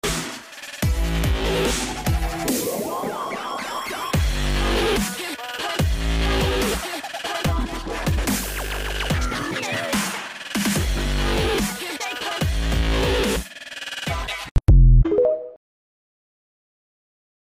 Vibrating bubble moving at a sound effects free download